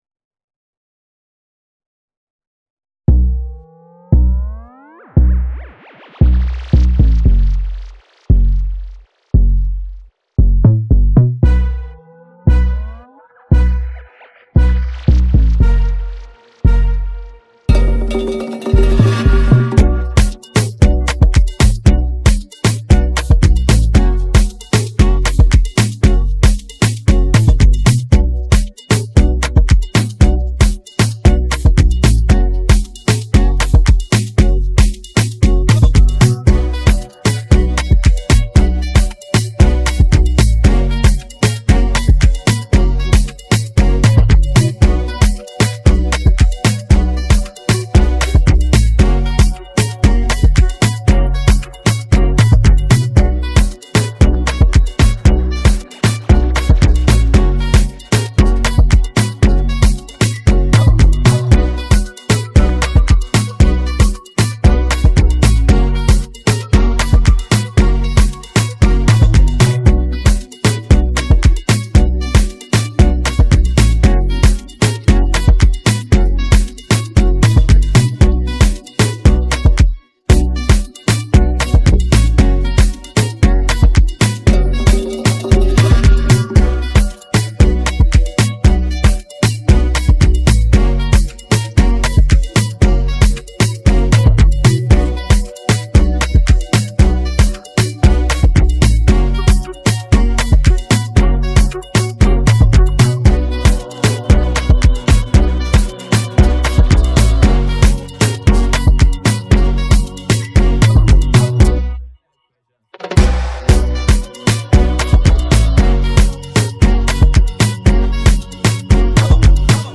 Afrobeats Amapaino
Tagged afrobeats , amapiano